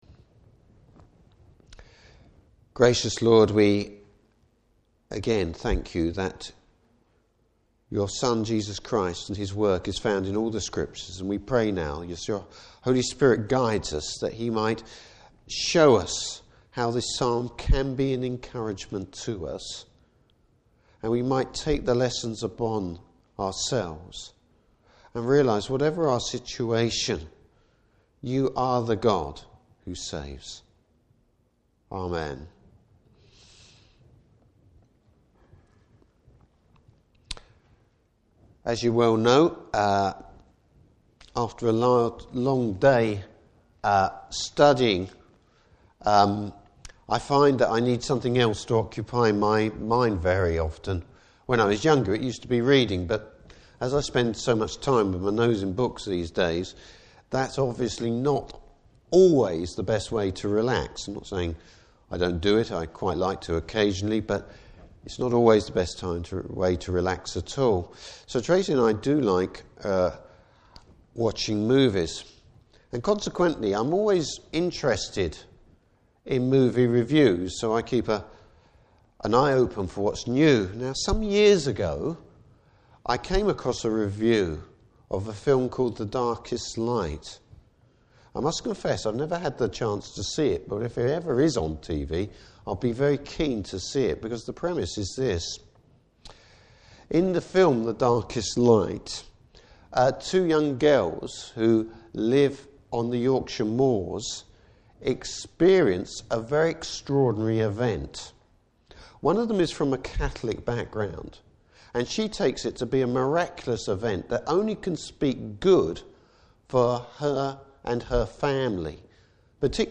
Service Type: Evening Service Finding an impression of Christ in a Psalm without a happy ending! Topics: Can there be blessing in difficult times?